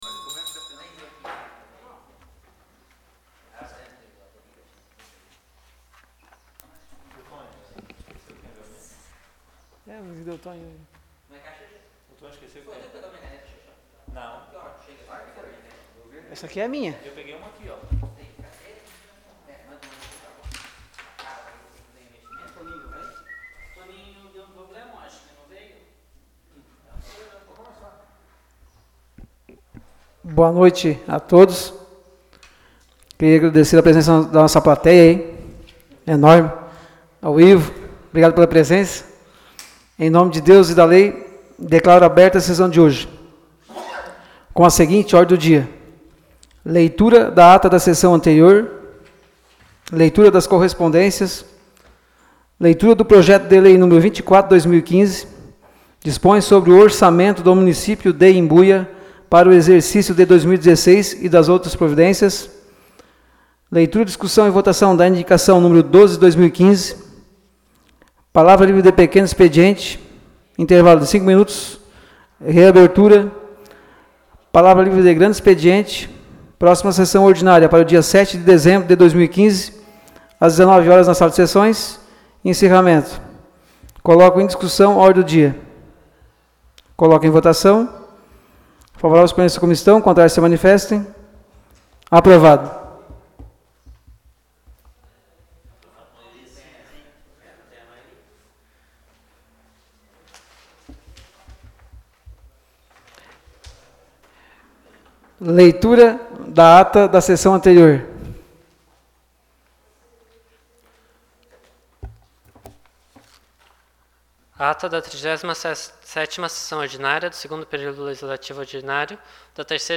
Áudio da Sessão Ordinária do dia 23 de novembro de 2015.